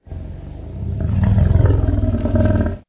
wolf_death_1.ogg